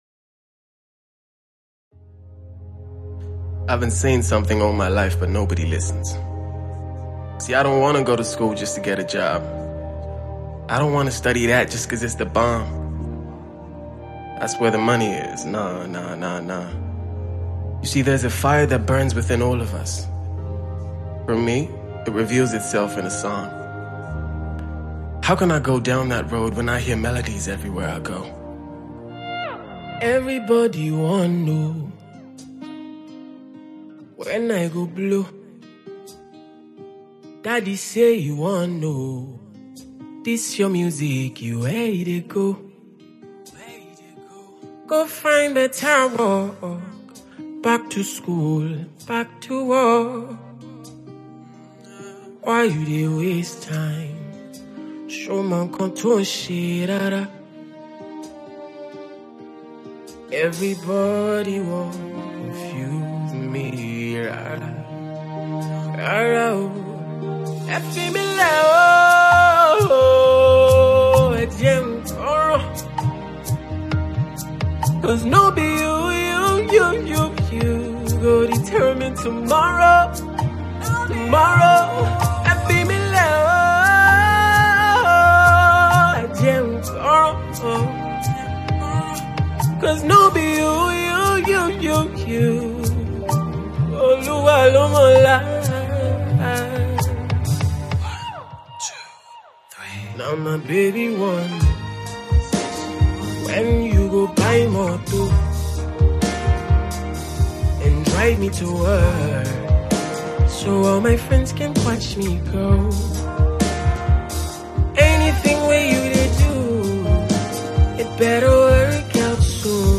an Afro Soul track